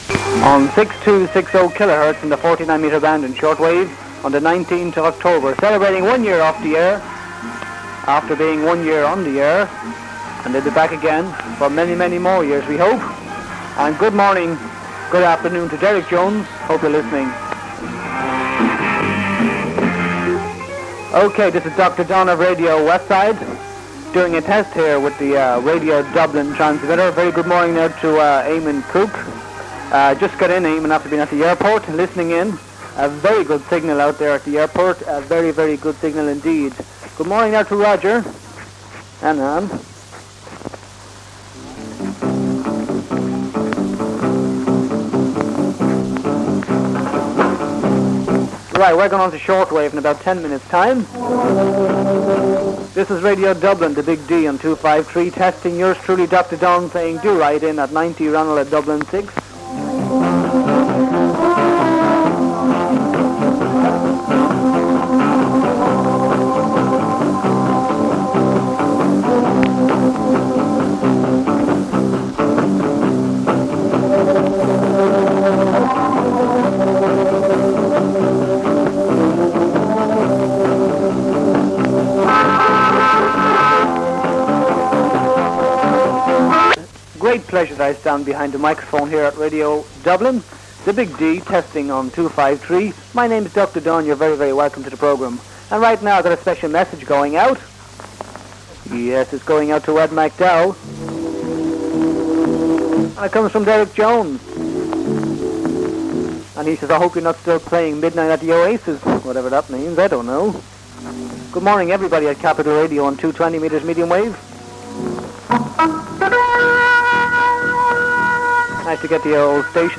Pirate Pioneers: early test transmission on Radio Dublin - Irish Pirate Radio Audio Archive